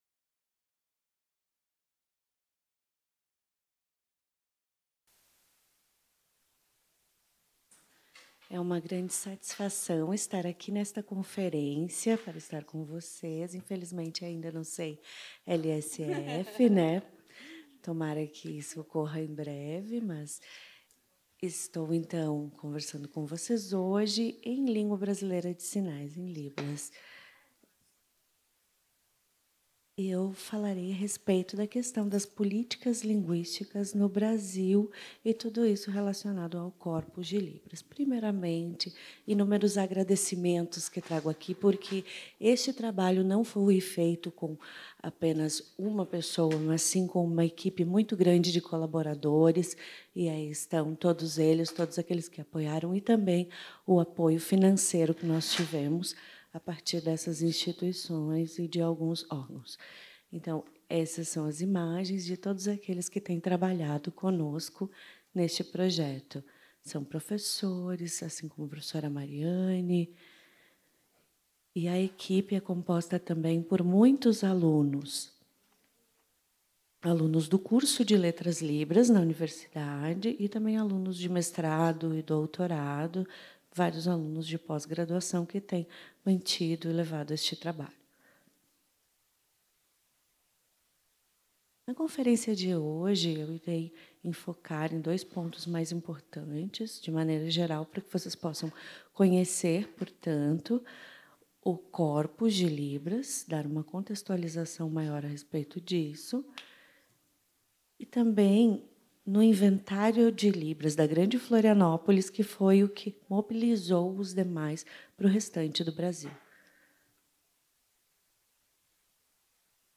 Conférence plénière